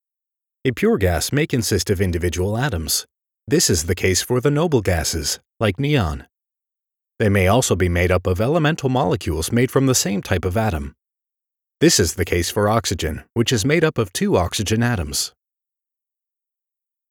mid-atlantic
middle west
Sprechprobe: eLearning (Muttersprache):
Trustworthy, Attention Grabbing, Believable, Authoritative, Sincere, Powerful, Friendly, Warm, Energetic, and Relatable are also proper adjectives.